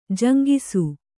♪ jaŋgisu